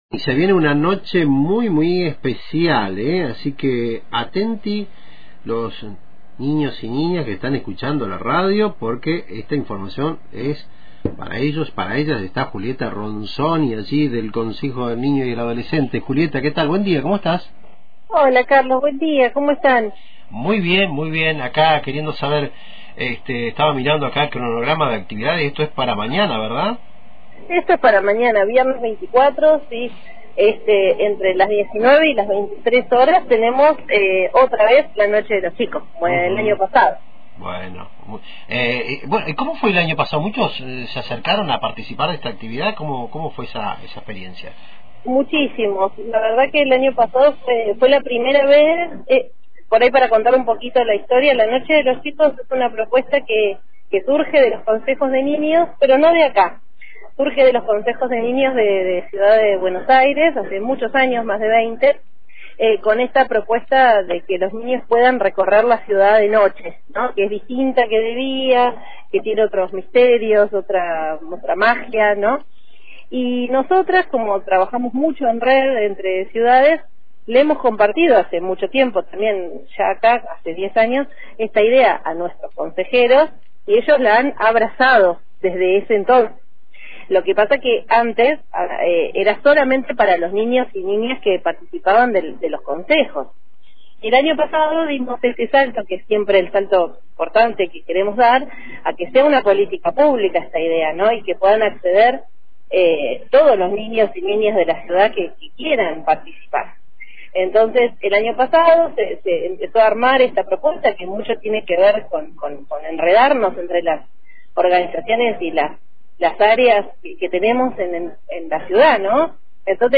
En el aire de Antena Libre conversamos